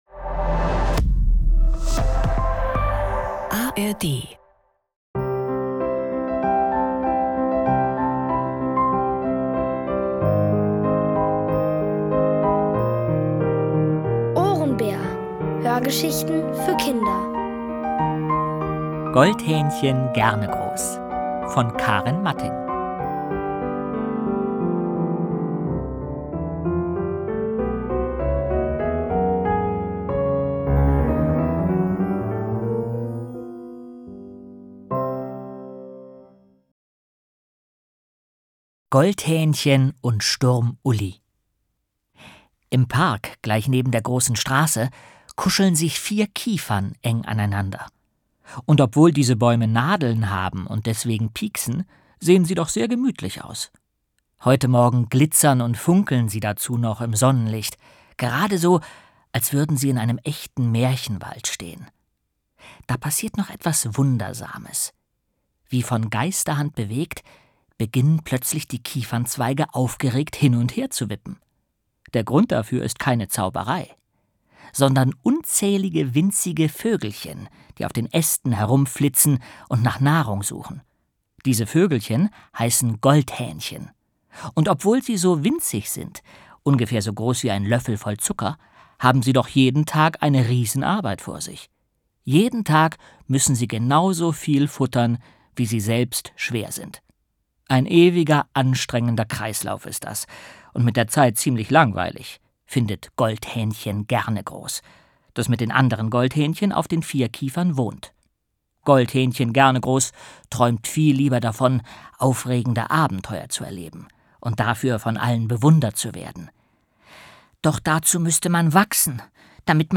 Es liest: Jens Wawrczeck.